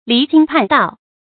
離經叛道 注音： ㄌㄧˊ ㄐㄧㄥ ㄆㄢˋ ㄉㄠˋ 讀音讀法： 意思解釋： 離：背離；叛：背叛；經：儒家經典著作；道：思想道德規范。